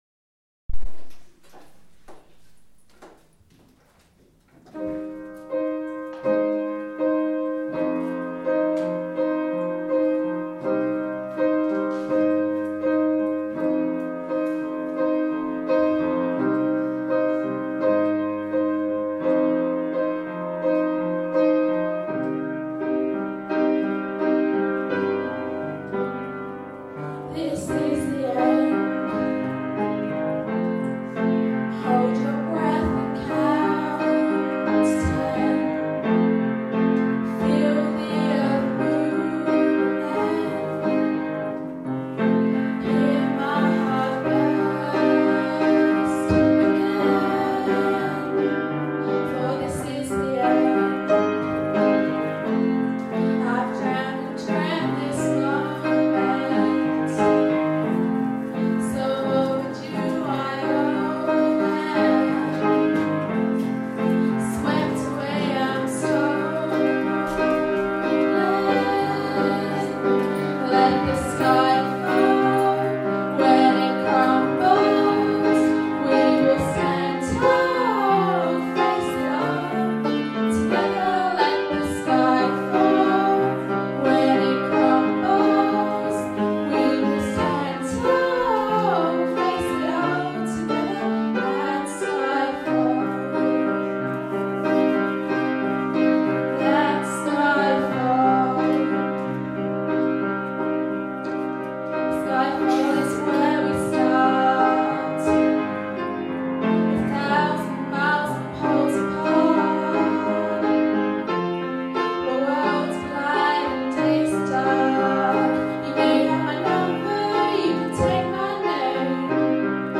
Singing lunchtime concert